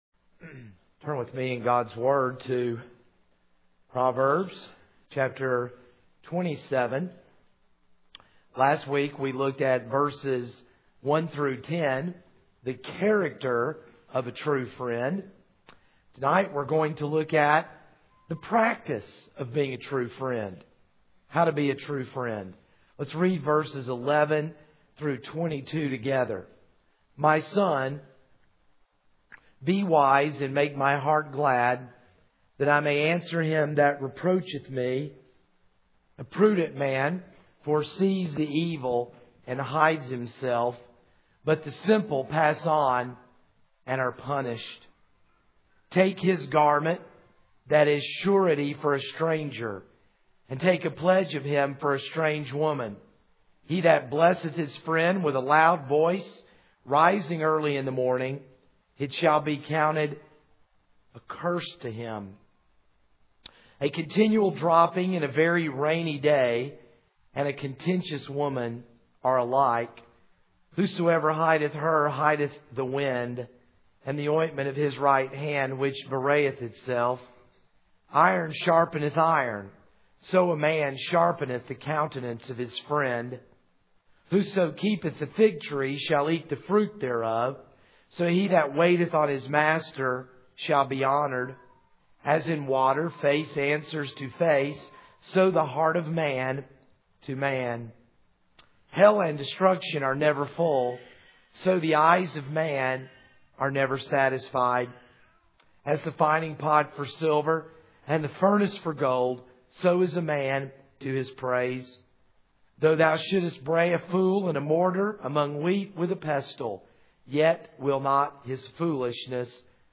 This is a sermon on Proverbs 27:11-22.